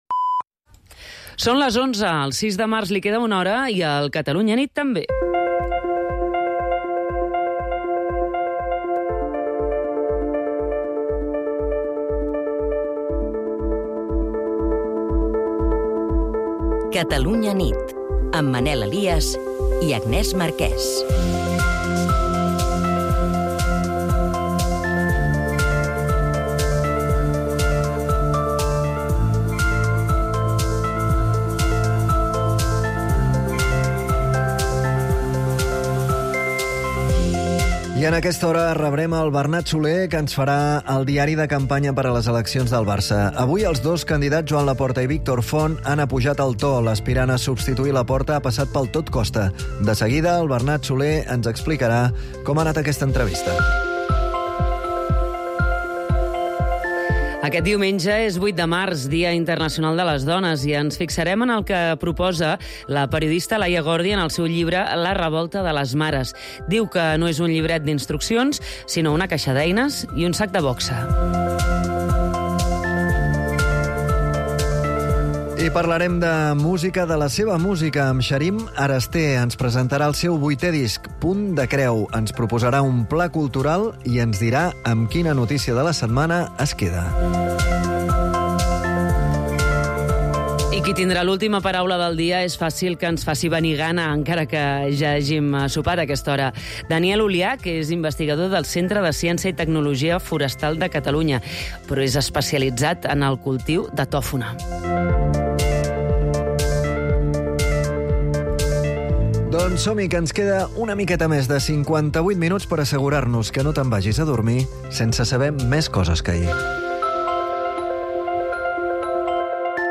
entrevistem la periodista